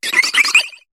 Cri de Chovsourir dans Pokémon HOME.